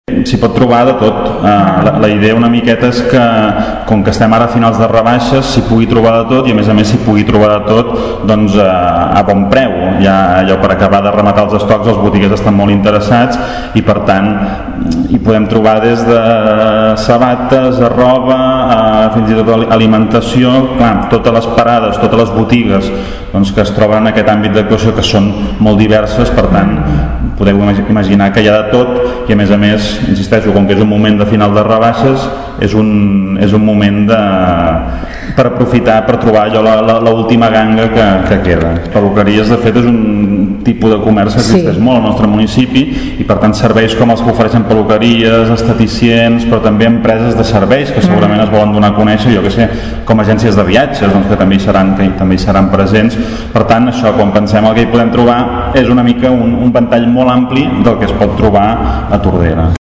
Marc Unió recorda que s’hi podrà trobar de tot i a bon preu, aprofitant que ja ens trobem a les segones rebaixes d’estiu.